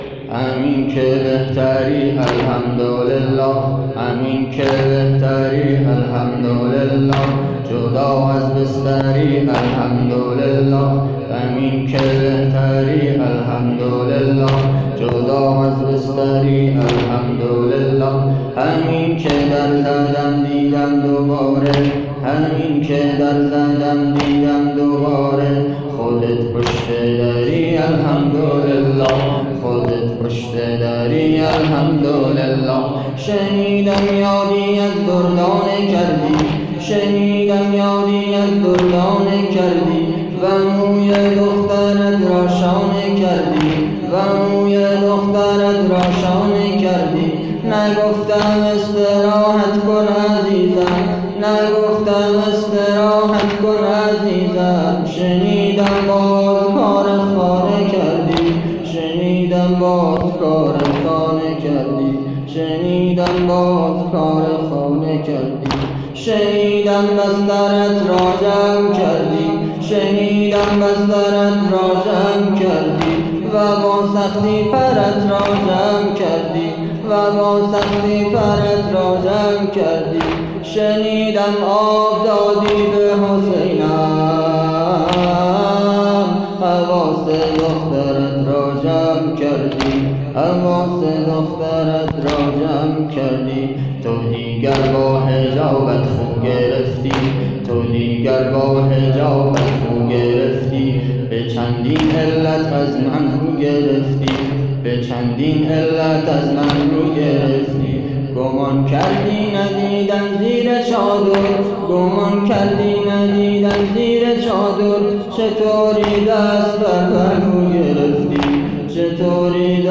فاطمیه 1401